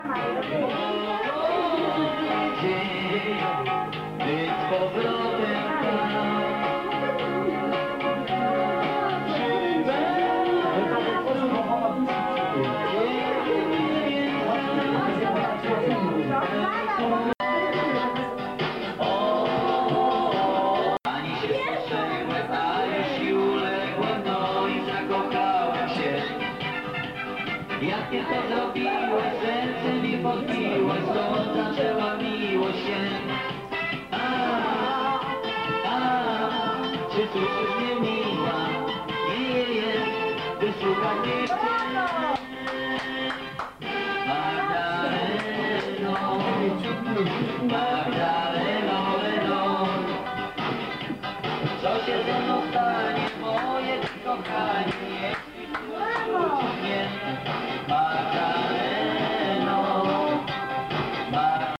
Disco-Polo